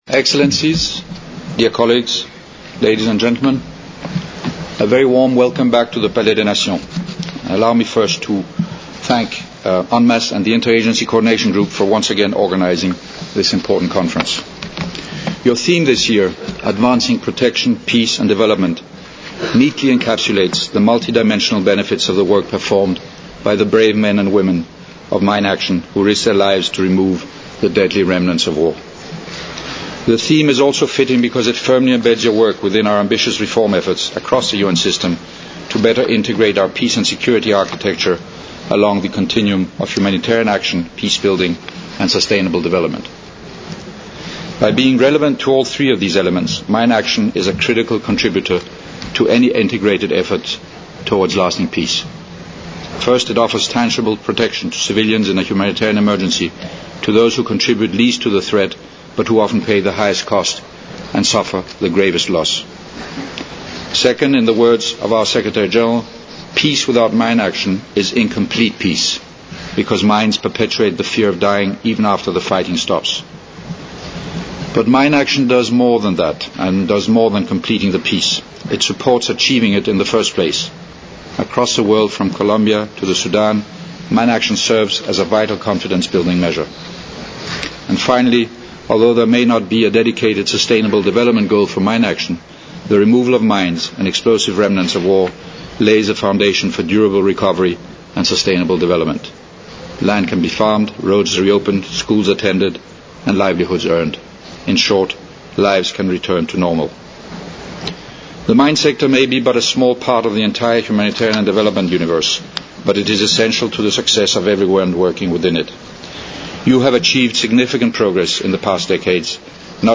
OPENING CEREMONY
• mr. michael møller, under-secretary-general, director-general of the united nations office at geneva